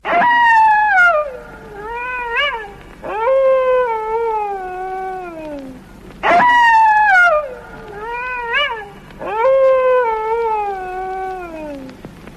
Uivo Lobo Selvagem
Famoso uivo de lobo selvagem, conhecido por ter sido executado no episódio O Abominável Homem Das Neves de Chapolin
uivo-lobo-selvagem.mp3